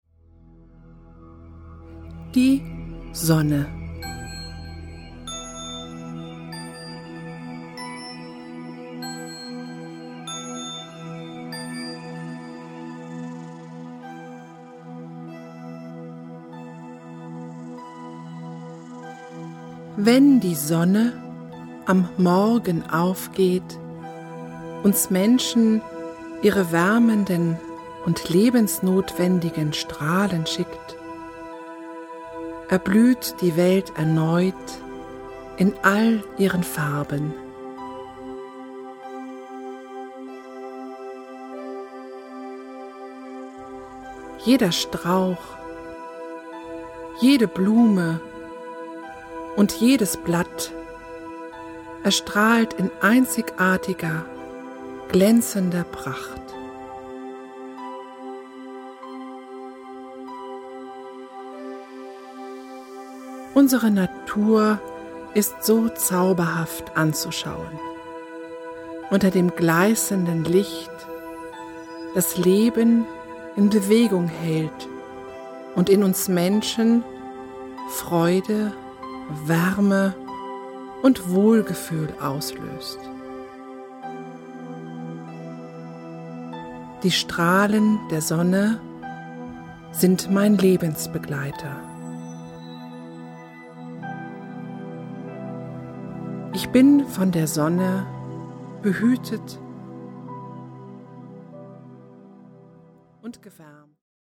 Eine innere Reise zur Ruhe mit entspannenden und bewußtseinserweiternden Texten, untermalt mit Naturgeräuschen und weichsanften Klängen.